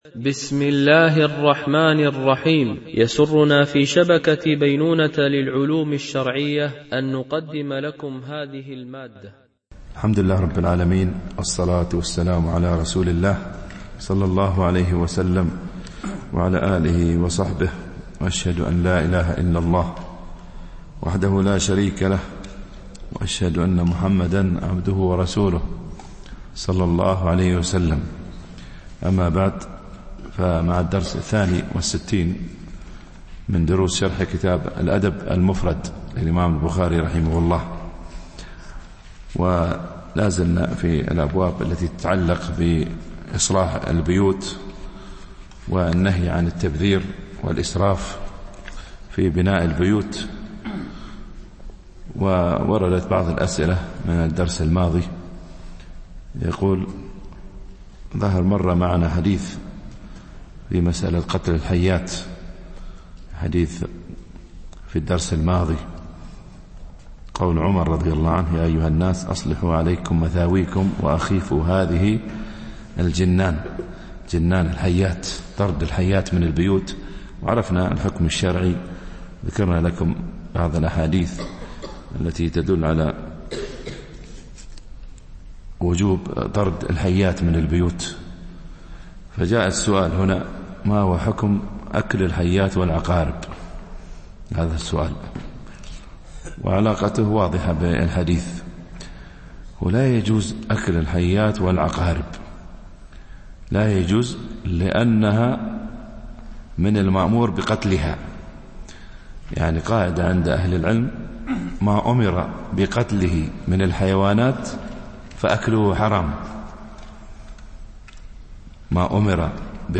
شرح الأدب المفرد للبخاري ـ الدرس62 ( الحديث 449-458 )